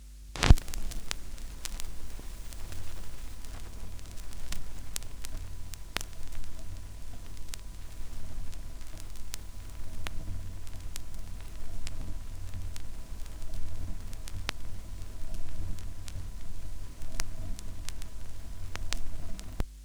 Je vous offre volontiers mes enregistrements de craquements de vinyle "bien vécu" que j'ai réalisés sur une Thorens TD 128 MK II... icon_mdr.gif
Les fichiers audio sont au format Aiff Stéréo 16 bit 44'100 Hz, perso je les assemble en réalisant des fondus croisés...
vinyl 1
Craquements vinyl 1.aif